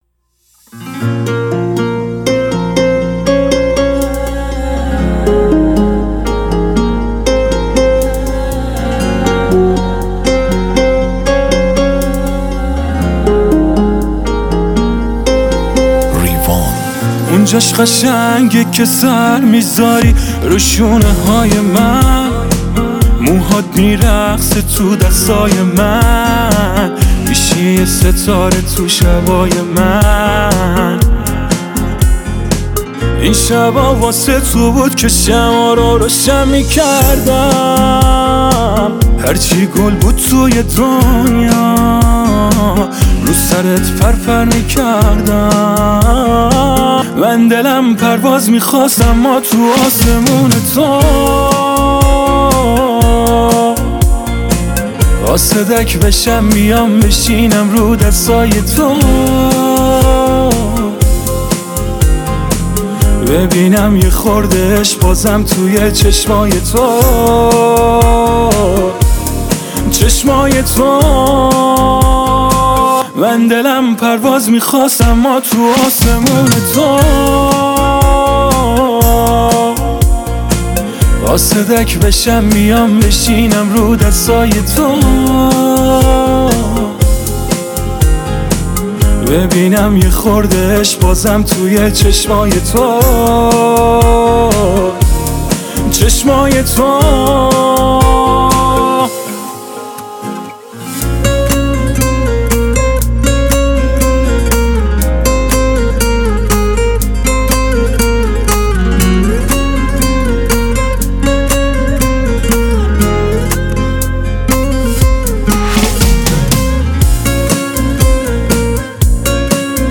پاپ
آهنگ با صدای زن